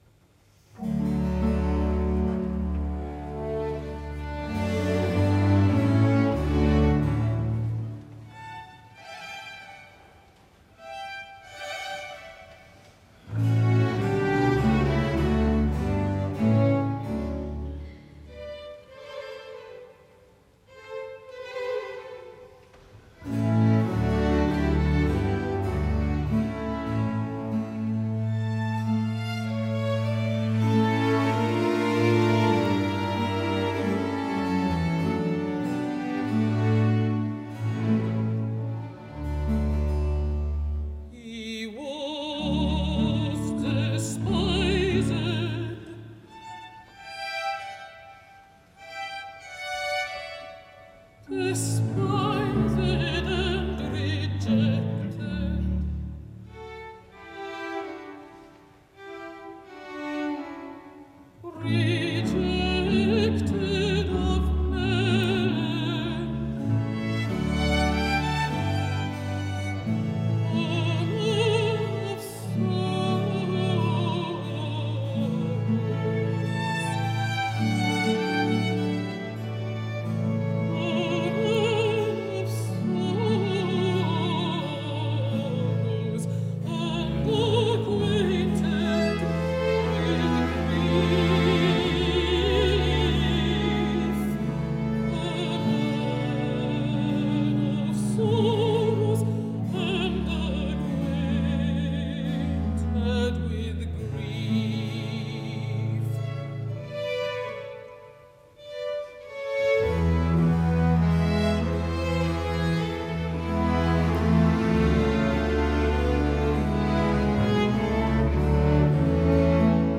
Ara escoltem a la notable Christine Rice cantant la meravellosa “He was despised” la primera ària de la segona part
MUSIKFEST ERZGEBIRGE – Eröffnungskonzert
Christine Rice, Alto
Sankt Marienkirche Marienberg